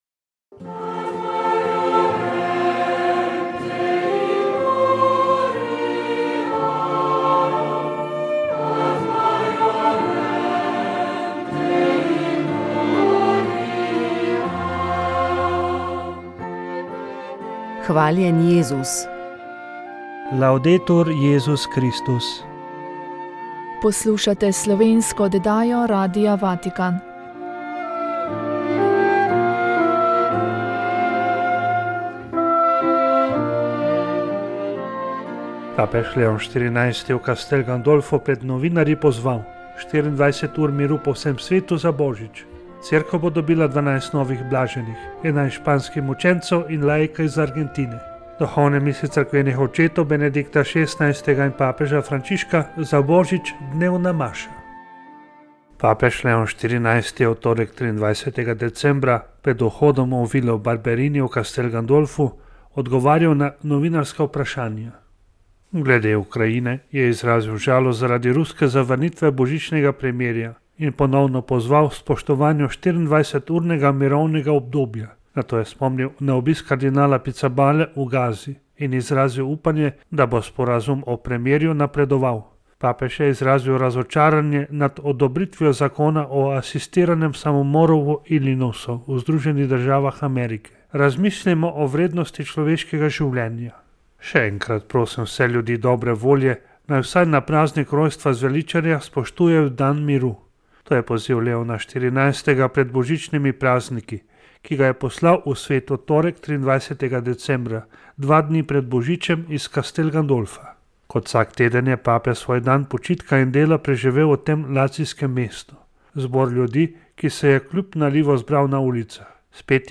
Ob Svetovnem dnevu Zemlje, ki ga obeležujemo dvaindvajsetega aprila, smo k pogovoru za poljudnejšo razlago podnebnih sprememb povabili vse bolj prepoznavnega in priznanega meteorologa, ki pri svojem profesionalnem delu združuje med drugimi tudi znanja fizike, matematike, tudi kemije, v prepletu z meteorologijo.